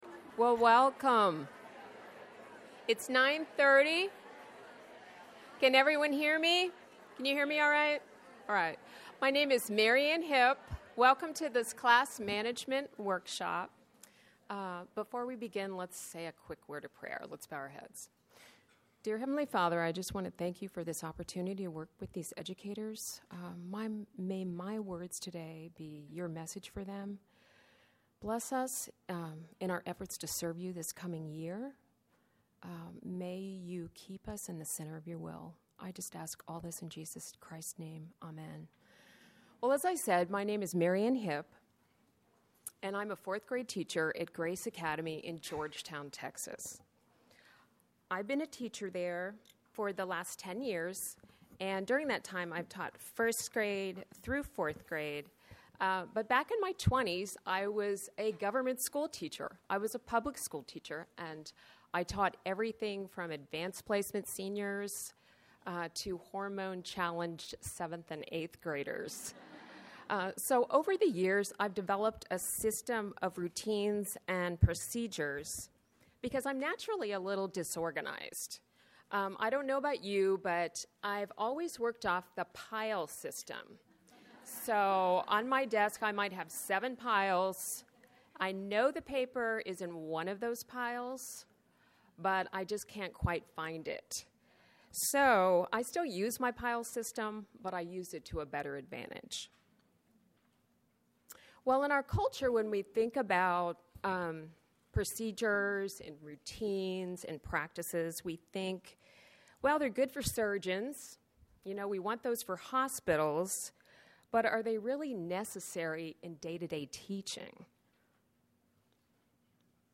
2012 Workshop Talk | 0:50:57 | 7-12, General Classroom
Speaker Additional Materials The Association of Classical & Christian Schools presents Repairing the Ruins, the ACCS annual conference, copyright ACCS.